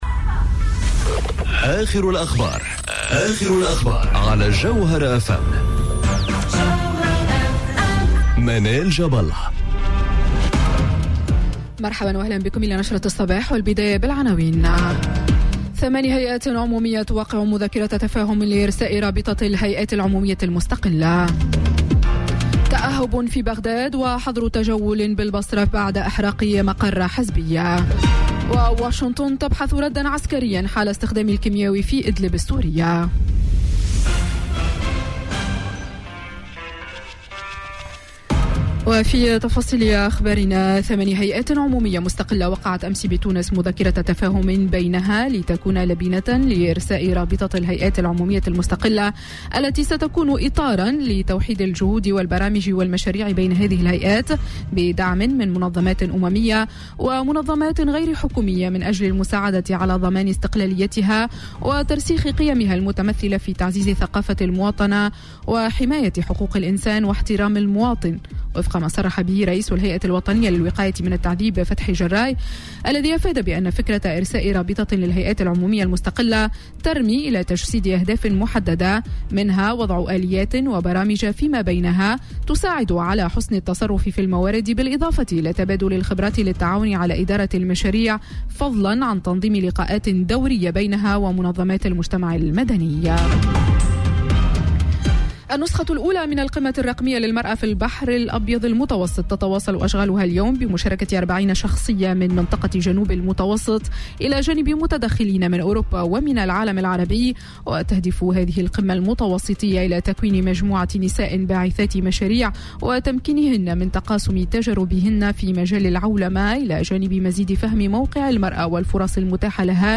نشرة أخبار السابعة صباحا ليوم السبت 8 سبتمبر 2018